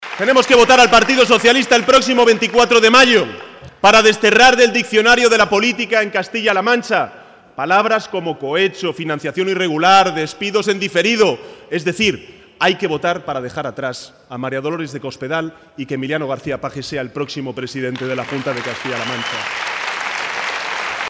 El candidato del PSOE a la Presidencia de Castilla-La Mancha, Emiliano García-Page, ha compartido esta tarde un gran acto público junto al secretario general socialista, Pedro Sánchez, en Alcázar de San Juan, ante más de 1.500 personas, y allí ha advertido una vez más que «el contador sigue en marcha, y va marcha atrás, como la región estos cuatro años, y seguimos sin conocer el programa electoral de Cospedal».